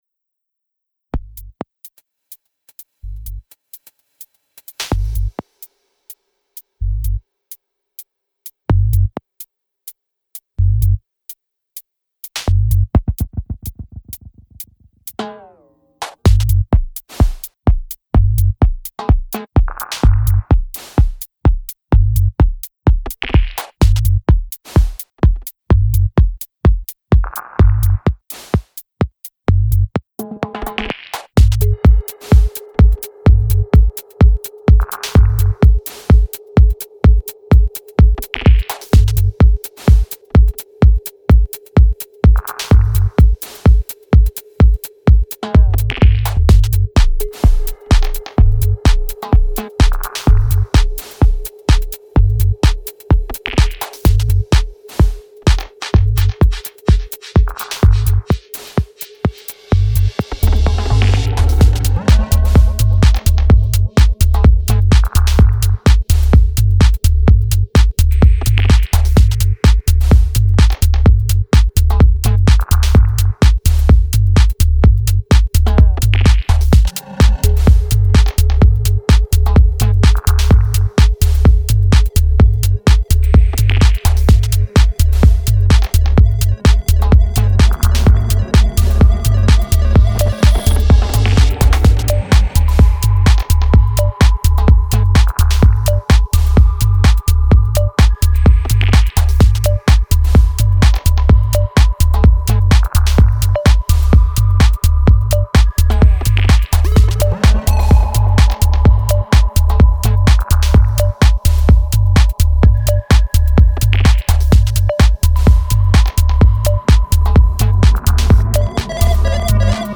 tribal, giving another dimension to the original version!